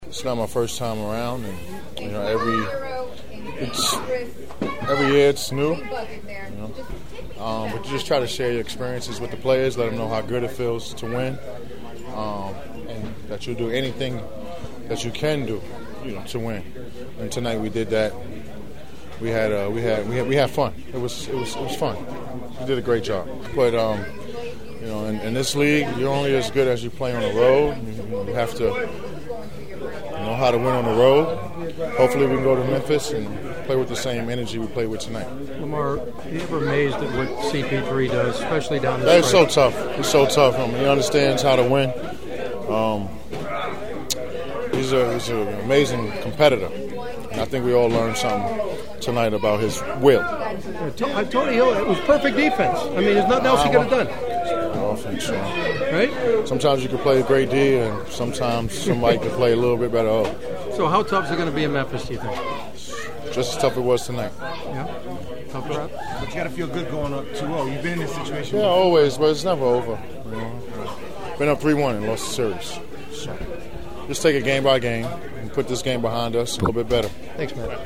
The following are my postgame Clipper interviews along with several preview thoughts for games 3 and 4 in Memphis on Thursday and Saturday (which you can hear of course on KFWB Newstalk 980).